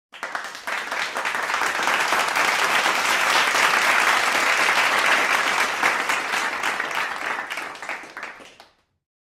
Applause
Category: Sound FX   Right: Personal